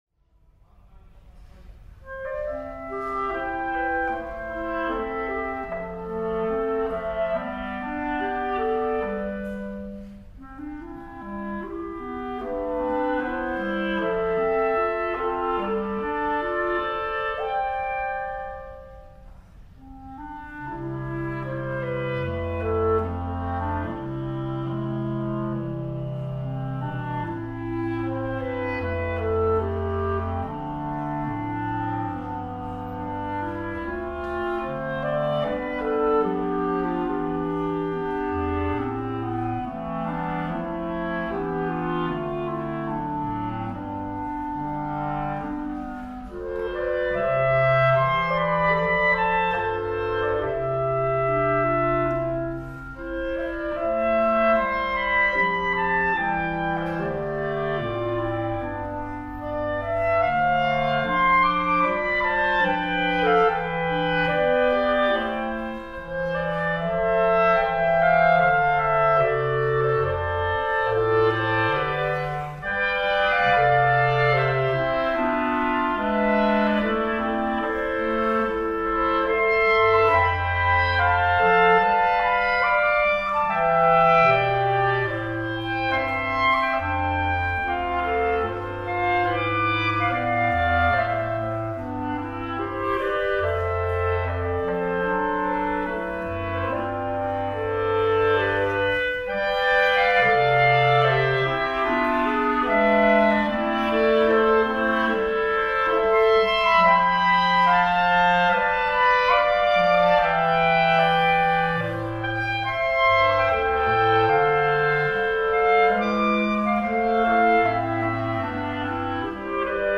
B♭ Clarinet 1 B♭ Clarinet 2 B♭ Clarinet 3 Bass Clarinet
单簧管四重奏
本编曲特别突显了单簧管的音色魅力。